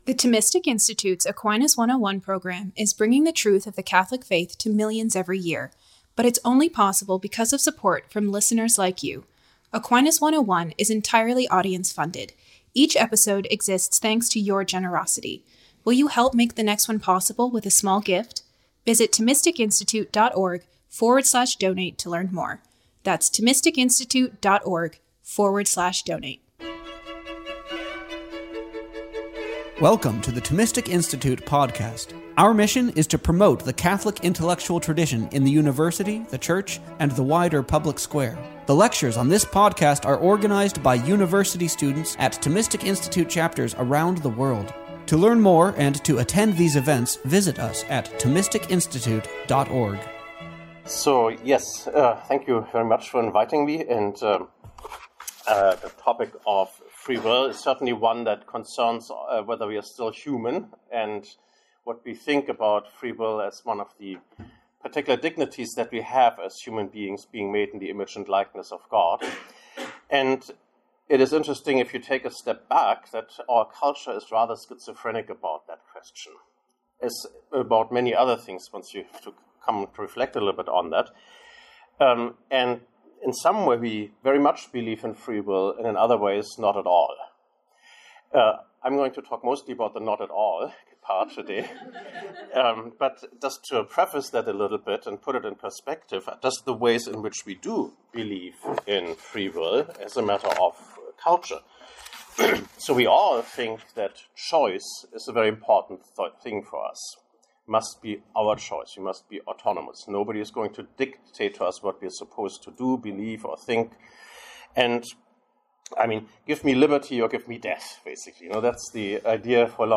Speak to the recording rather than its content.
This lecture was given on September 14th, 2024, at Dominican House of Studies.